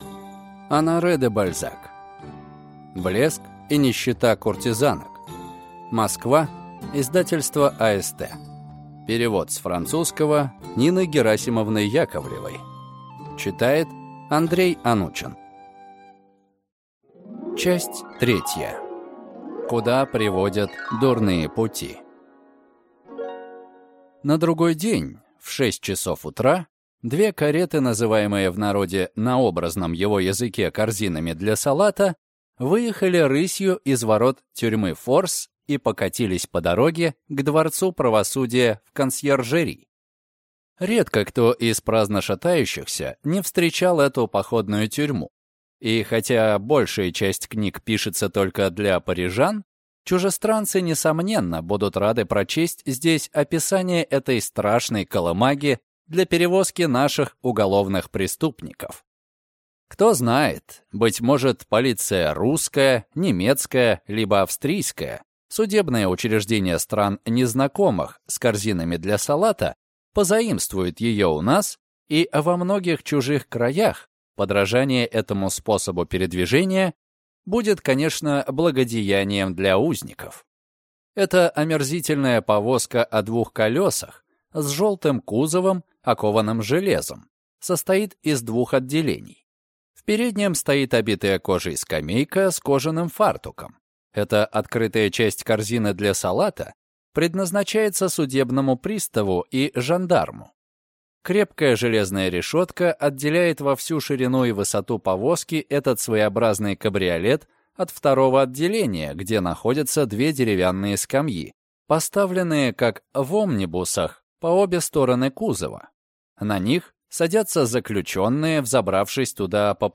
Аудиокнига Блеск и нищета куртизанок (Часть 2) | Библиотека аудиокниг